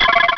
sound / direct_sound_samples / cries / beautifly.wav